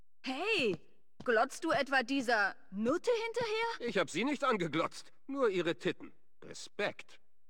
FOBOS-Dialog-Carbon-Bürger-005.ogg